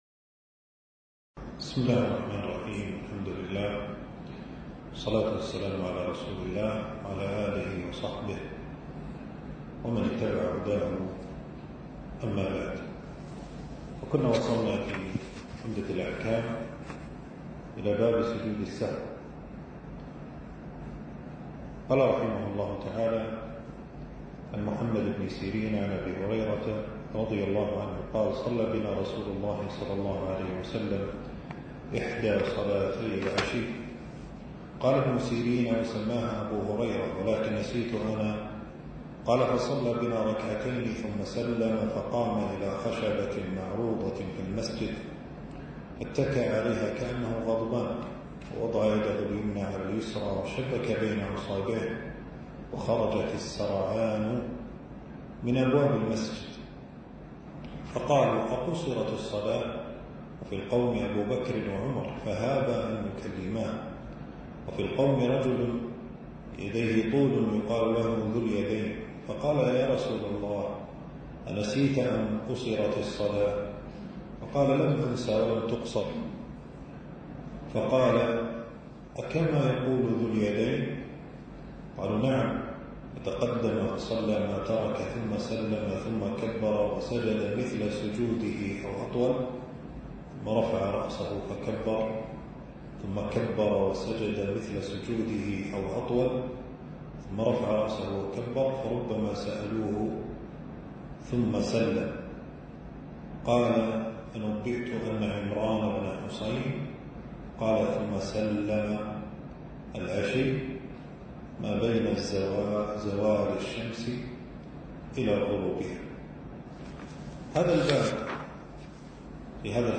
المكان: درس ألقاه في 4 جمادى الثاني 1447هـ في مبنى التدريب بوزارة الشؤون الإسلامية.